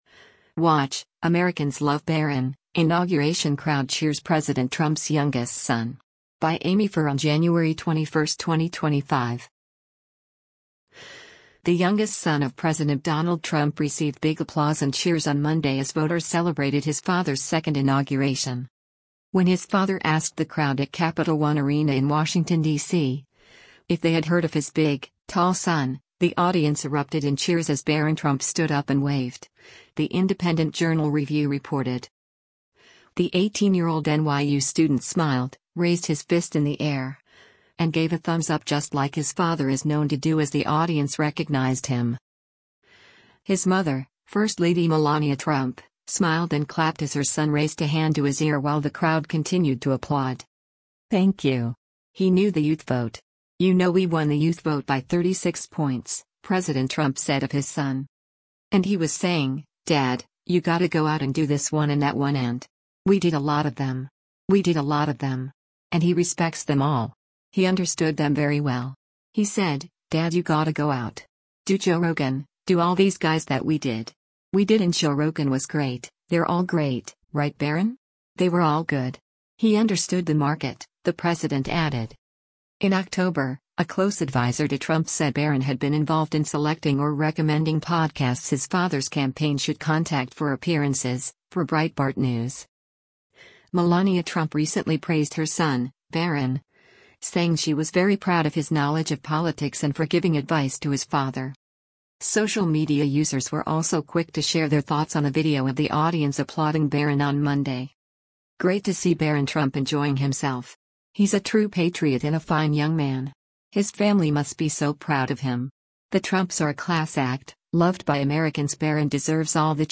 The youngest son of President Donald Trump received big applause and cheers on Monday as voters celebrated his father’s second inauguration.
When his father asked the crowd at Capital One Arena in Washington, DC, if they had heard of his “big, tall son,” the audience erupted in cheers as Barron Trump stood up and waved, the Independent Journal Review reported.
His mother, first lady Melania Trump, smiled and clapped as her son raised a hand to his ear while the crowd continued to applaud: